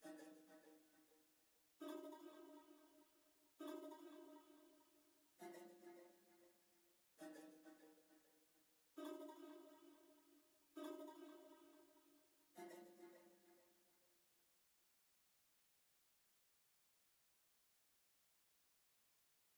Boomin-Beat-Starter-0_Motion Pad.wav